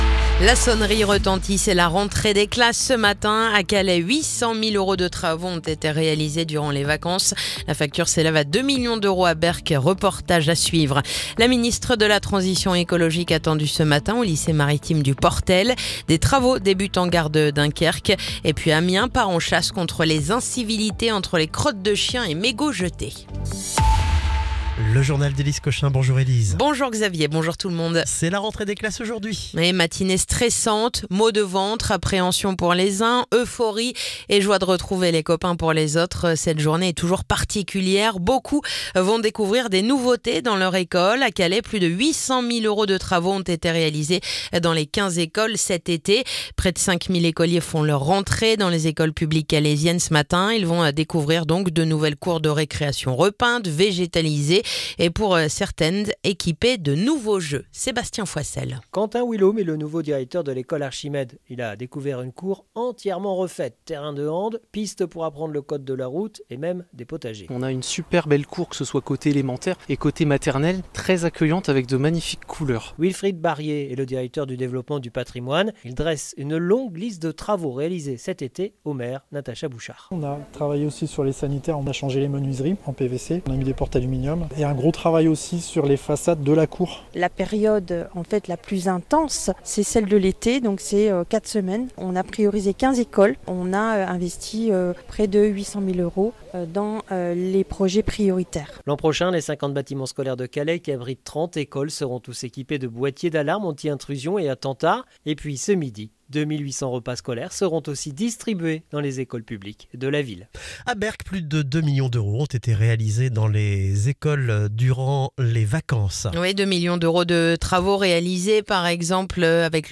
Le journal du lundi 1er septembre